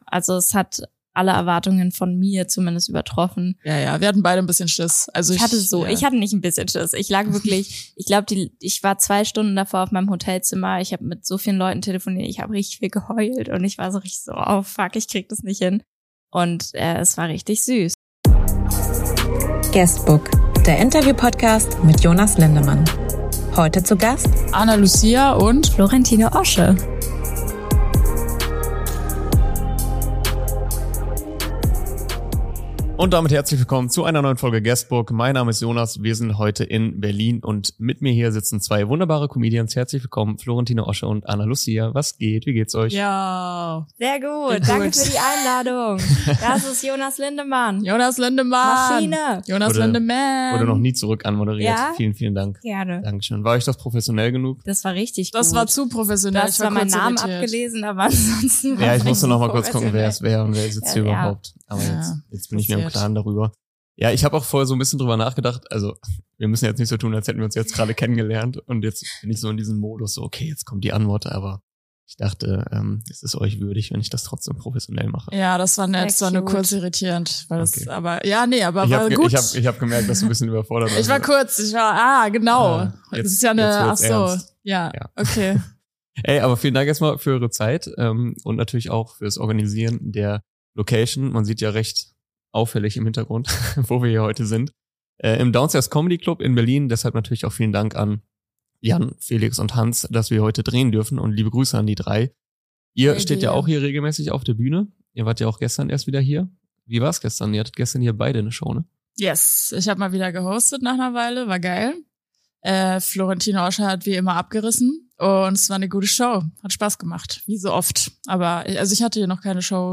Interview ~ GUESTBOOK